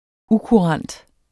Udtale [ ˈukuˌʁɑnˀd ]